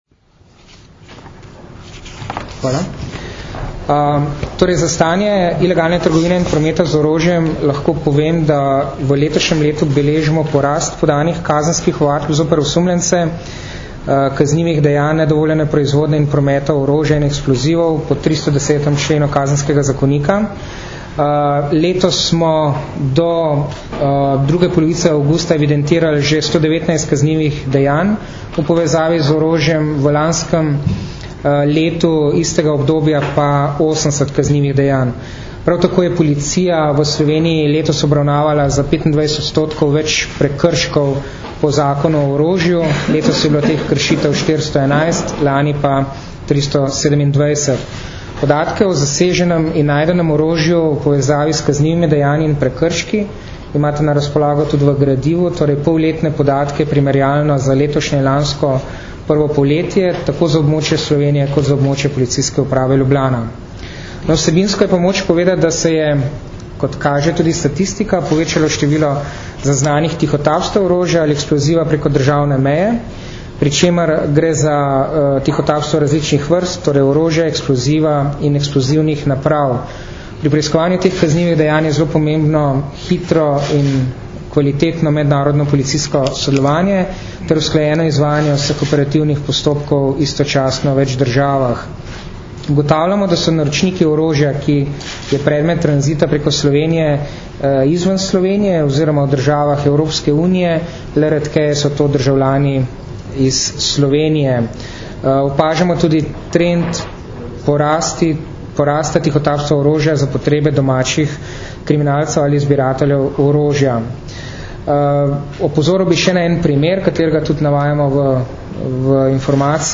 Zaseg večje količine orožja in streliva, problematika ilegalne trgovine in prometa z orožjem - informacija z novinarske konference